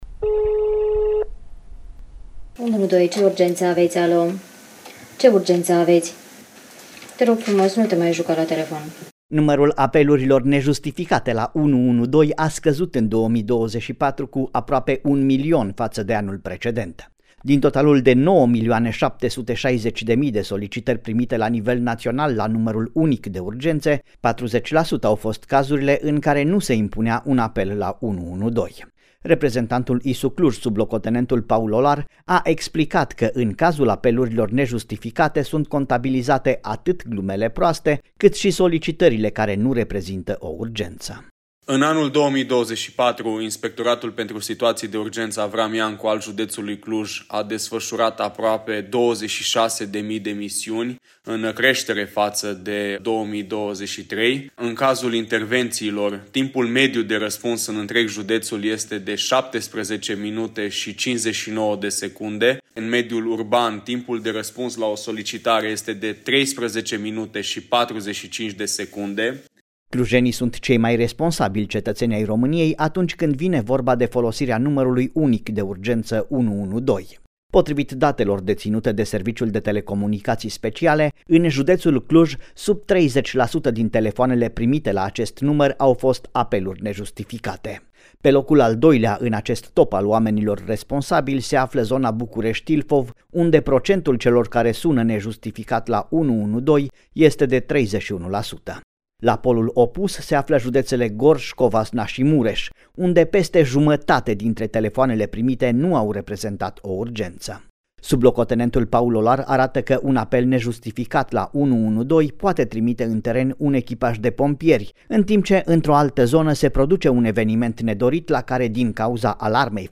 reportaj-statistica-apeluri-112.mp3